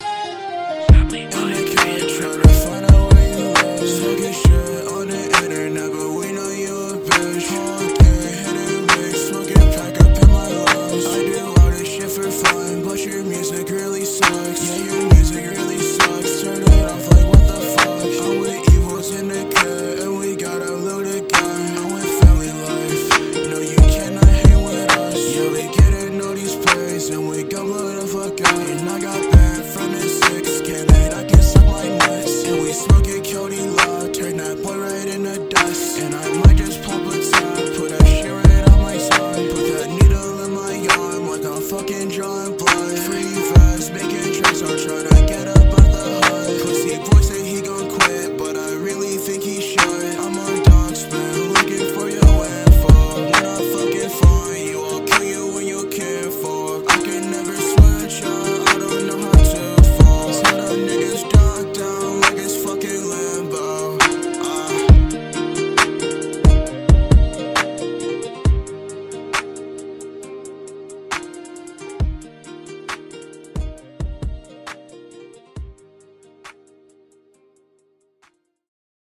who else would like to rap like me?